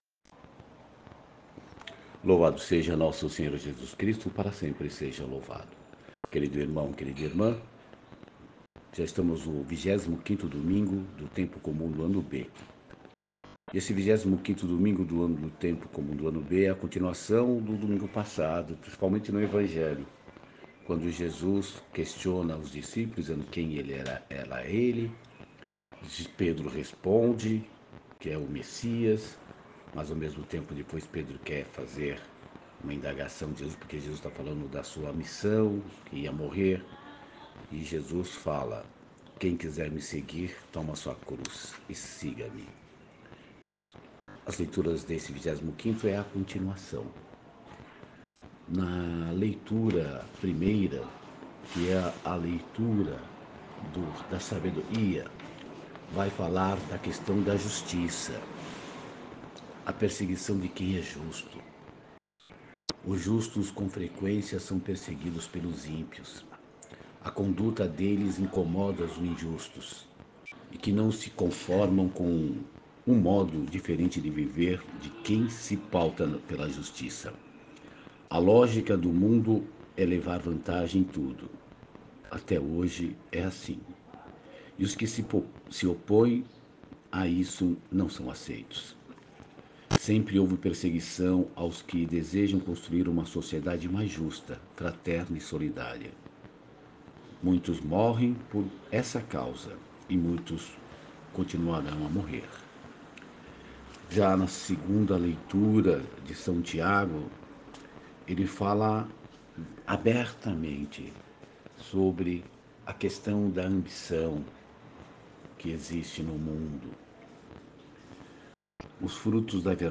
Meditação e Reflexão da 25º Domingo do Tempo Comum. Ano B
Reflexao-25.ogg